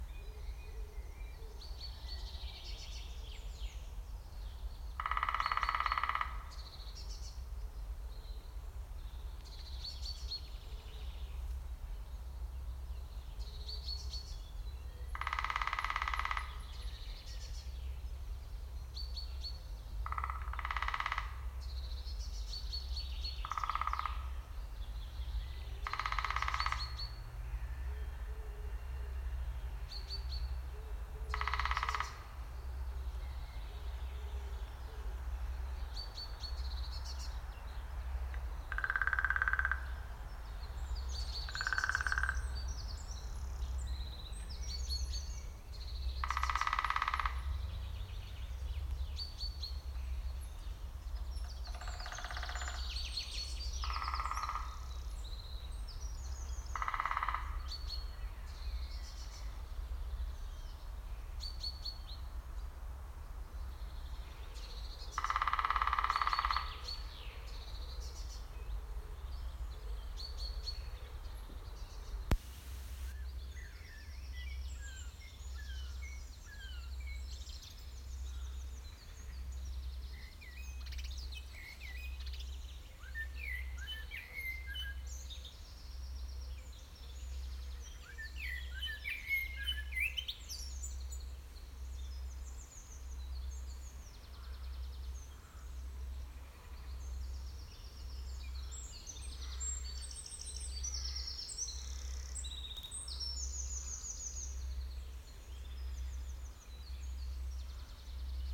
Large Woodpecker and Blackbird
Large Woodpecker drumming away and finding sounds, and later the gentle song of a Blackbird, in Pålsjö skog, Helsingborg.